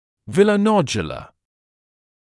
[ˌvɪləu’nɔʤjələ][ˌвилоу’ноджйэлэ]виллонодулярный